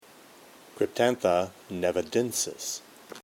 Pronunciation/Pronunciación:
Cryp-tán-tha  ne-va-dén-sis